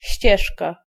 Ääntäminen
IPA : /treɪl/ IPA : /tɹeɪl/